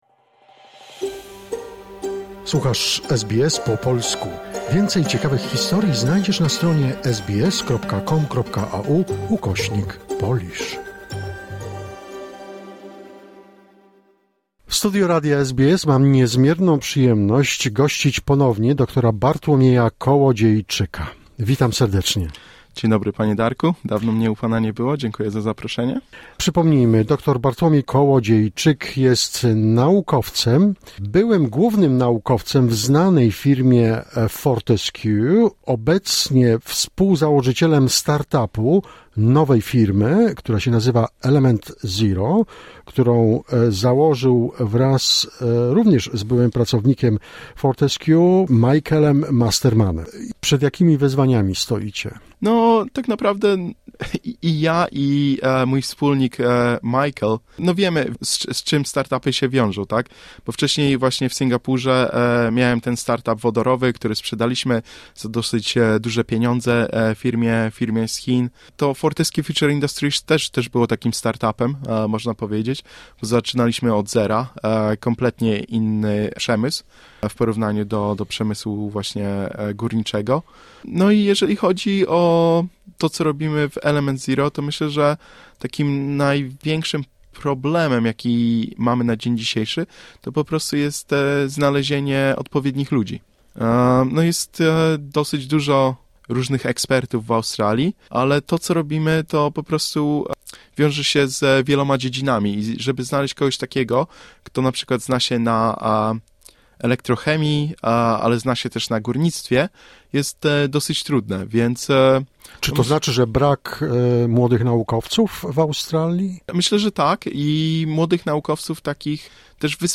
Druga część rozmowy